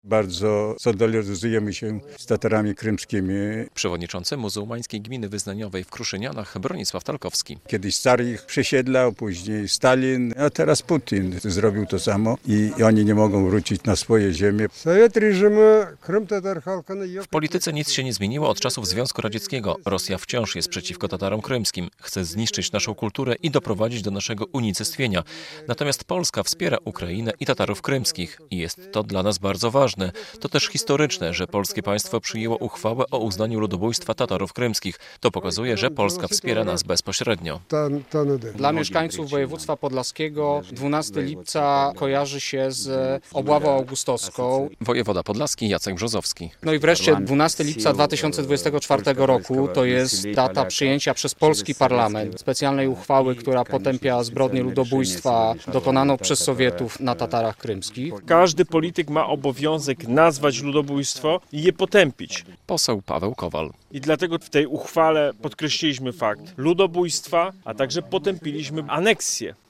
Lider Tatarów krymskich Mustafa Dżemilew podczas konferencji prasowej podkreślał, że w polityce rosyjskiej nic się nie zmieniło od czasów Związku Radzieckiego.